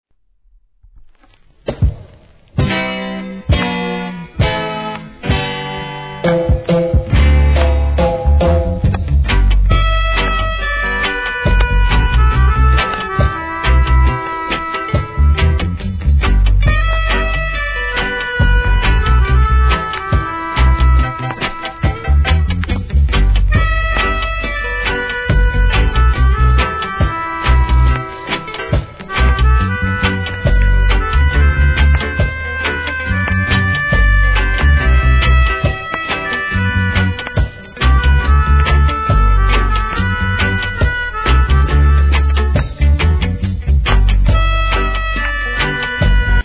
REGGAE
Inst.物!!